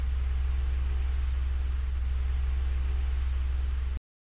Since your clip is fairly periodic, as an exercise, I tried taking your audio, duplicating to a new track, time-shifting it to find a section that roughly corresponds with the original, but without the thud.